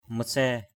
mathraiy.mp3